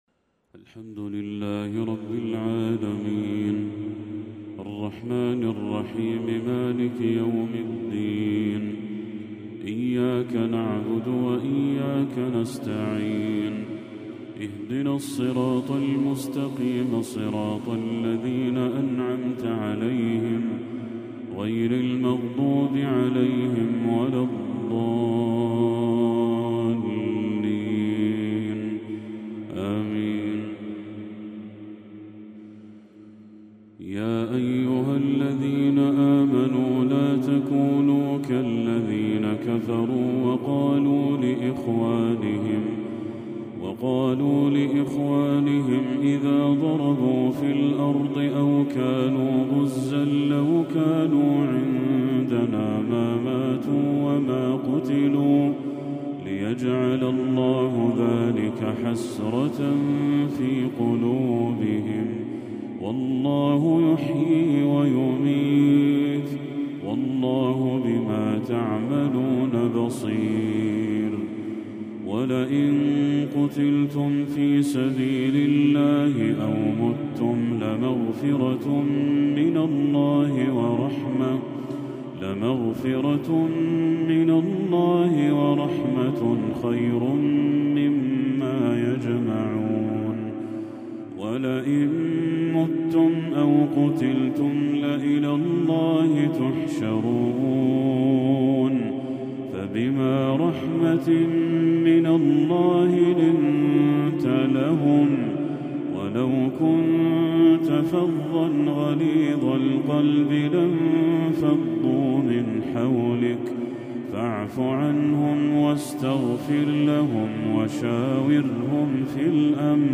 تلاوة من سورة ال عمران للشيخ بدر التركي | عشاء 28 ربيع الأول 1446هـ > 1446هـ > تلاوات الشيخ بدر التركي > المزيد - تلاوات الحرمين